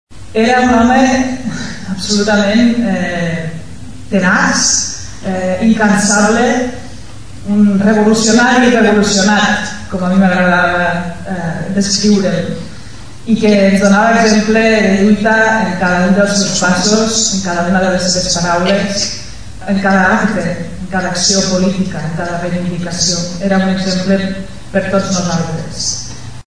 Va ser un acte senzill, però emotiu: diversos parlaments i algunes projeccions amb imatges de la seva vida política, acompanyades de música en directe, van servir per recordar-lo.